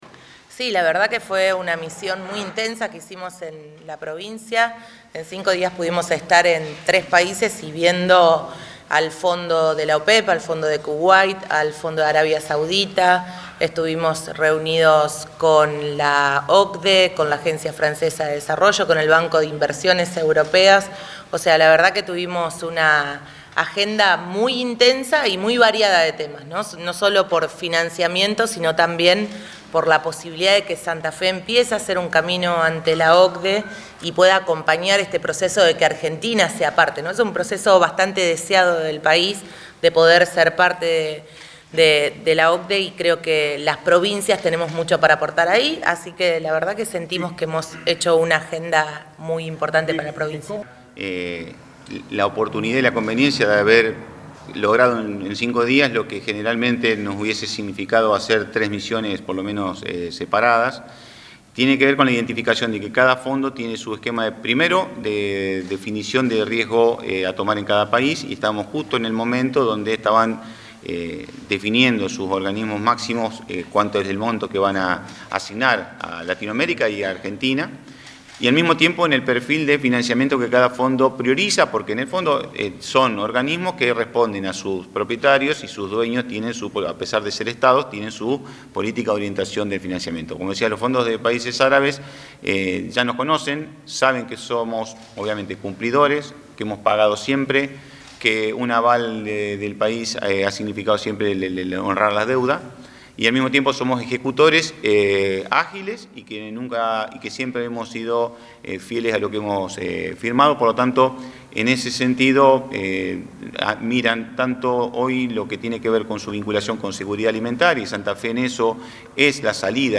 La vicegobernadora de la provincia, Gisela Scaglia, junto al ministro de Economía, Pablo Olivares, y el director de la Agencia de Cooperación Económica y Financiamiento Externo, Gonzalo Saglione, brindaron una conferencia de prensa en la que hicieron un balance de la misión institucional a Europa para buscar financiamiento externo para proyectos de infraestructura y desarrollo económico.